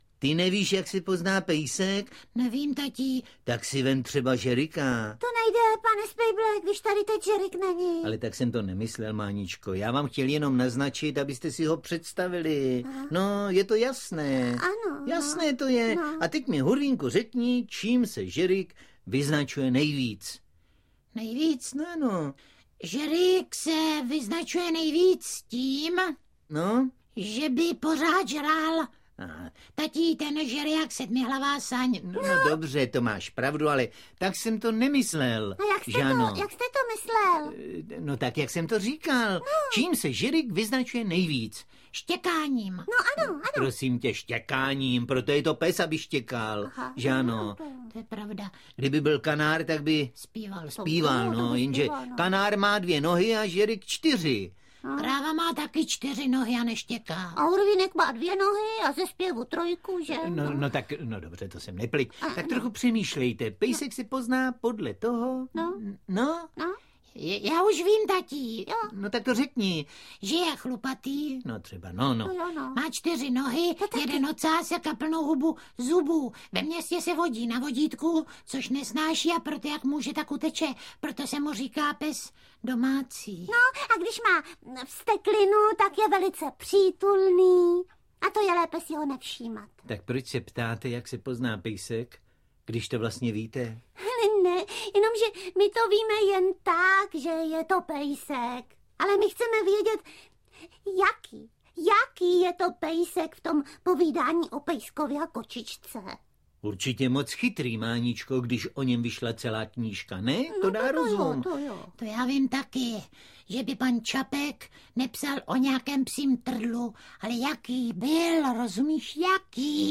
Hrátky a pohádky audiokniha
Ukázka z knihy